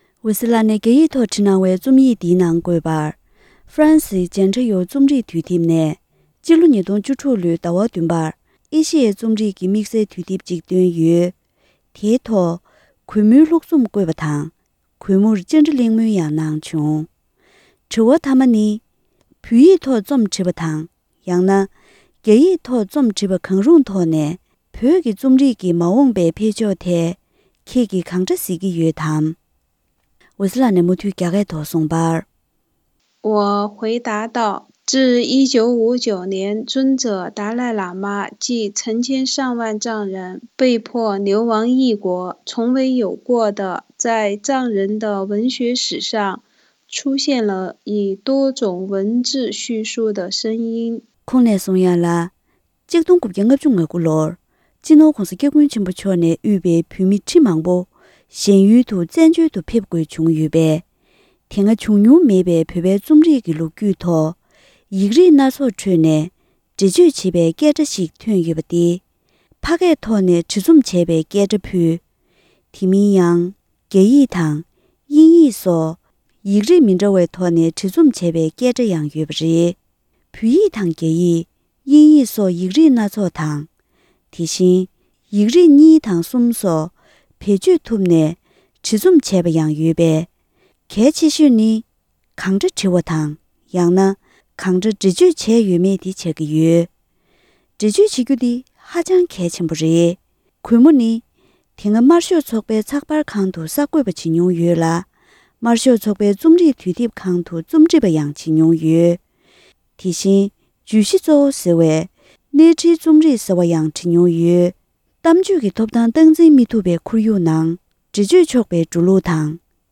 ཧྥ་རན་སིའི་Jentayuདུས་དེབ་ནས་འོད་ཟེར་ལགས་སུ་བཅར་འདྲི།